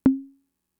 Index of /kb6/Fricke_MFB-522/CONGAS
Conga (4).WAV